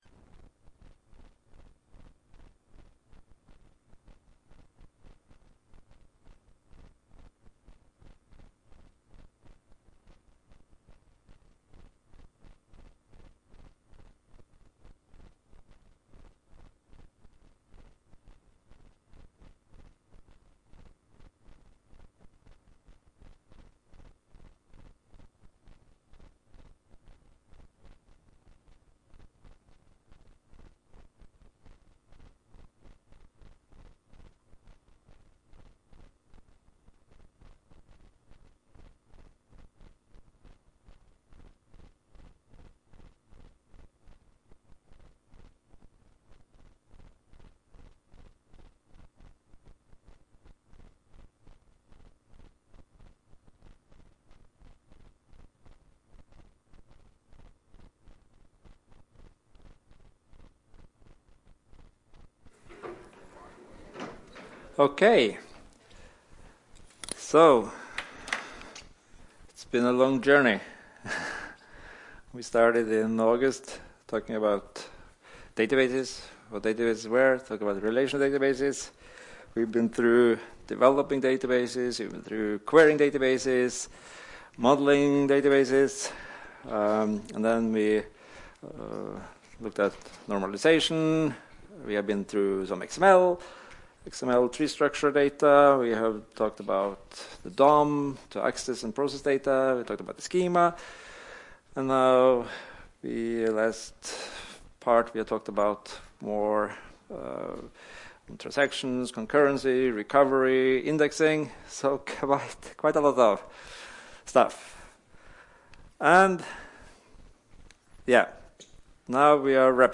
Rom: Smaragd 1 (S206)